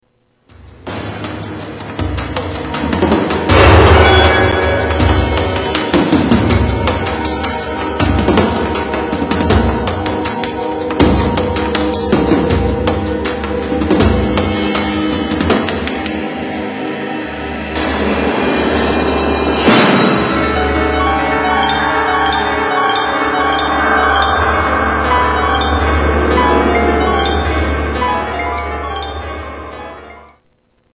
Television Music